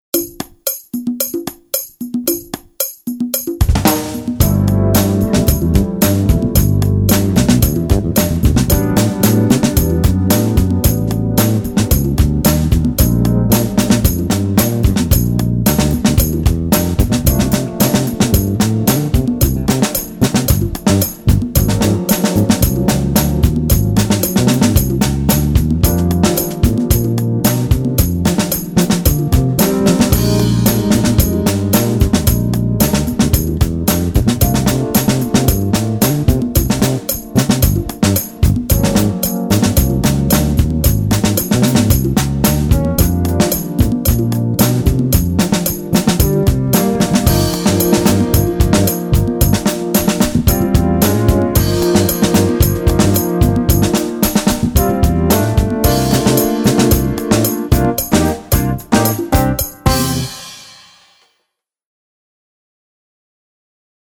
Esercizi per basso e batteria
SEDICESIMI CASSA.mp3